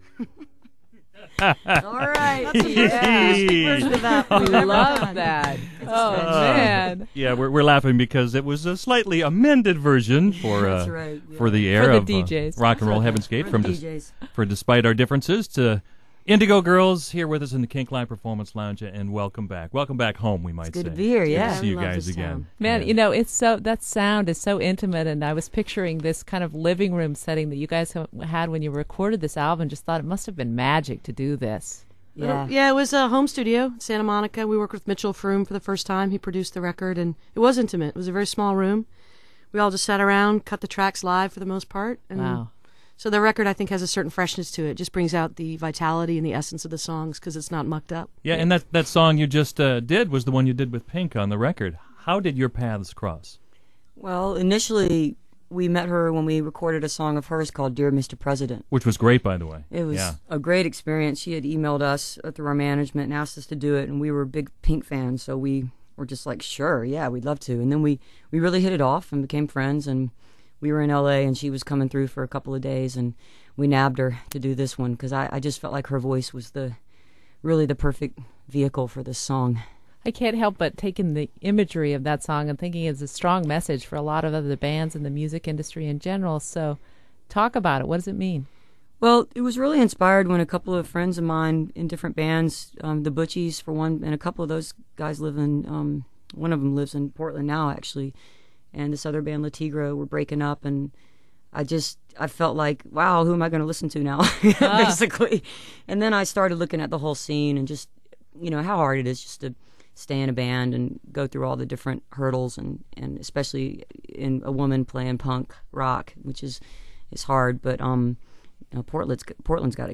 (acoustic duo show)
interview